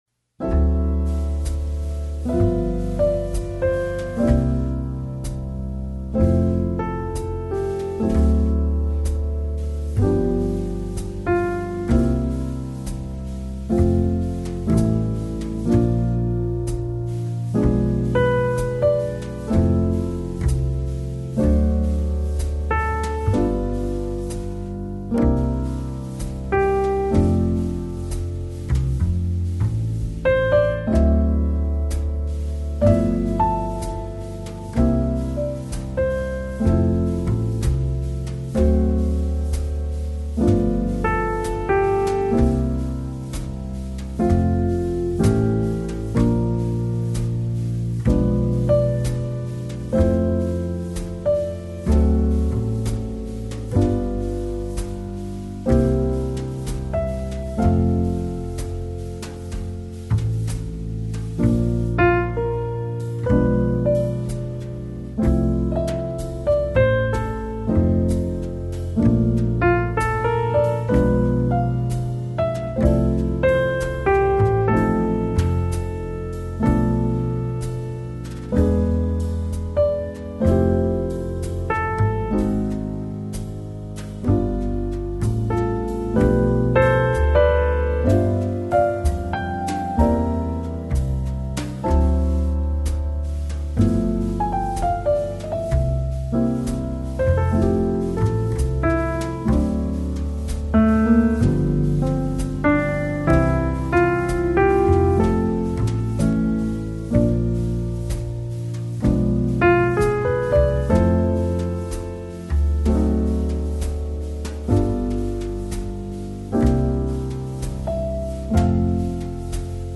Жанр: Pop Jazz, Easy Listening